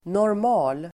Uttal: [nårm'a:l]